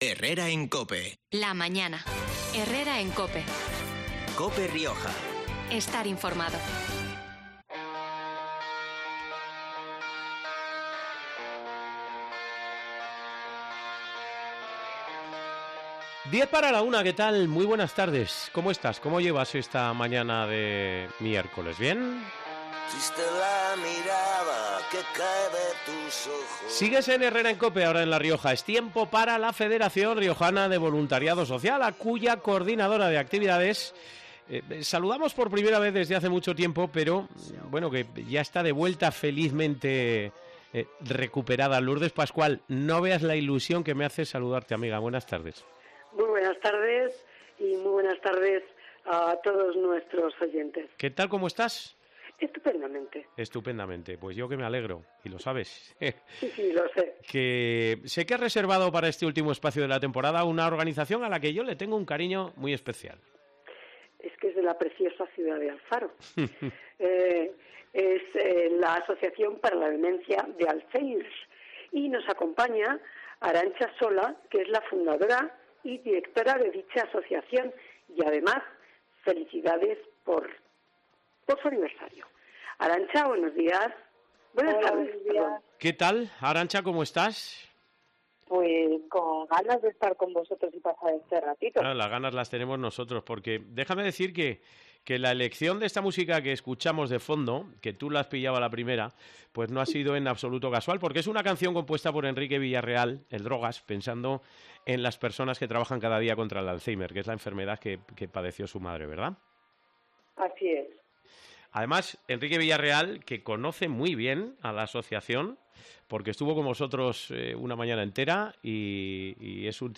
Usuarios del centro de día de 'ADA-Alfaro' relatan su día a día